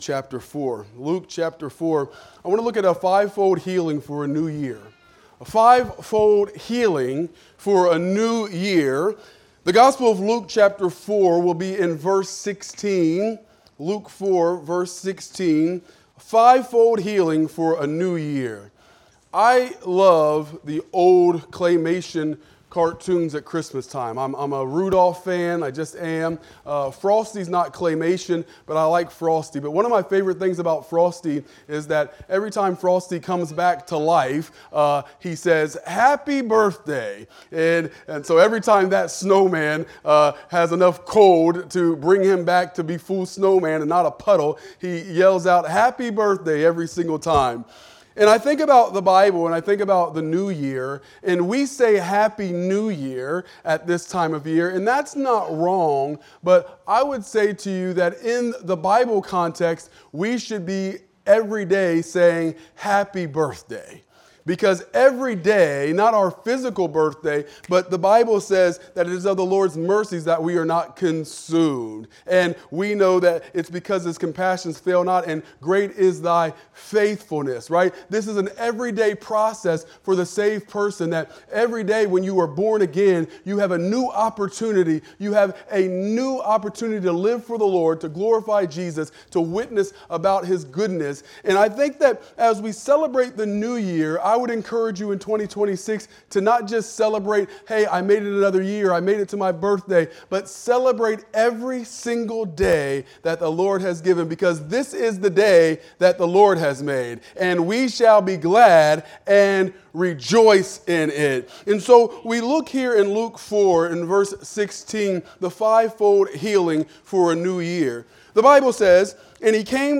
Wednesday Midweek Service